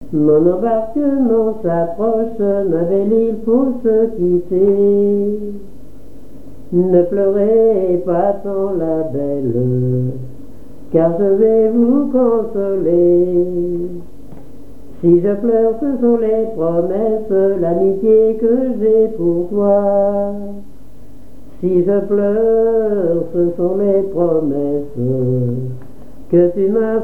Conversation autour des chansons et interprétation
Pièce musicale inédite